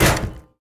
hit_metal_crate_02.ogg